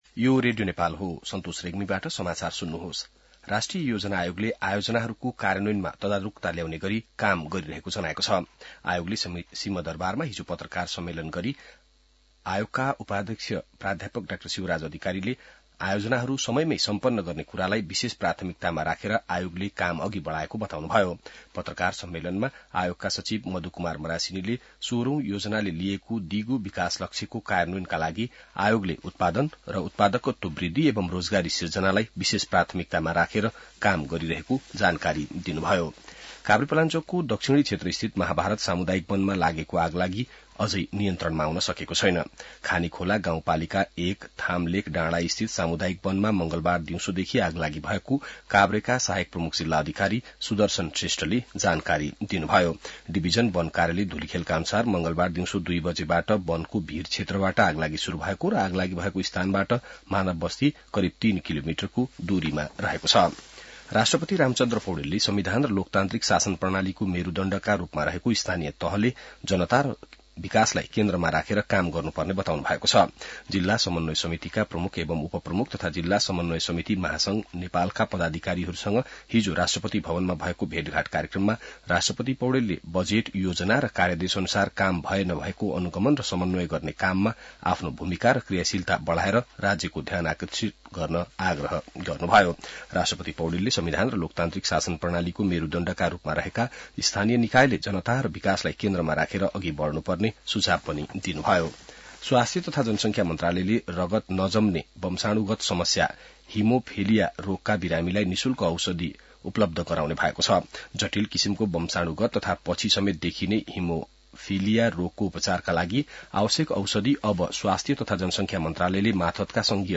बिहान ६ बजेको नेपाली समाचार : १२ माघ , २०८१